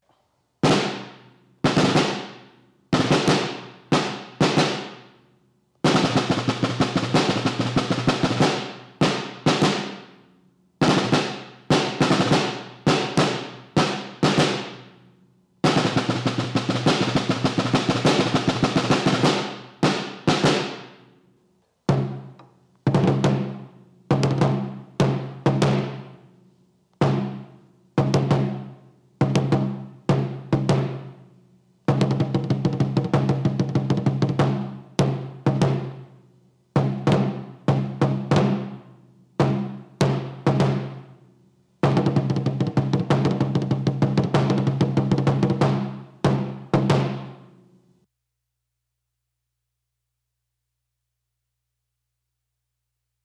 ▷Tambor Militar 35X16 Standar Ref. 04705 Negro Bordon de seda 【Musical San Francisco】
Sin duda, es uno de los tambores más demandados por los músicos de agrupaciones y amantes de la semana santa por su potente sonido y bonito timbre.
redoble-caja-MSF-2-CAJA_MEDIANA-1.wav